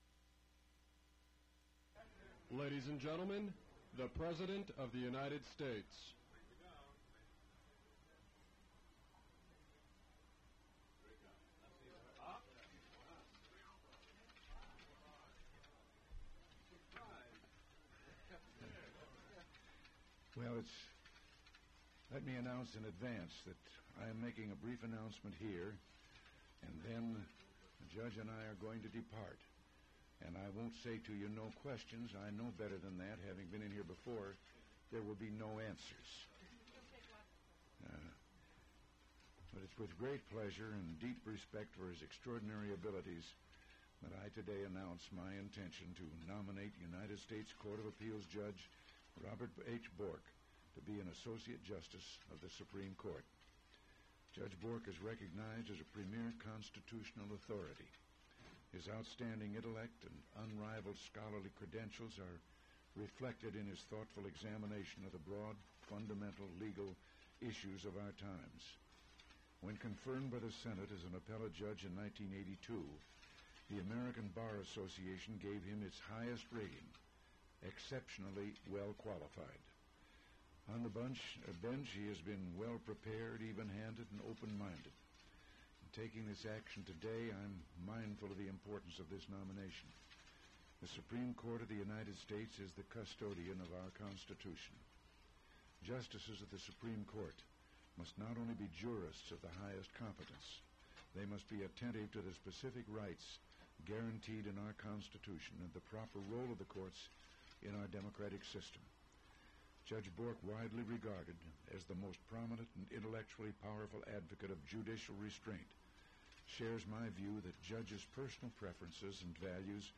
Remarks of the President during Announcement of Judge Robert Bork as Nominee to Supreme Court White House Press Lobby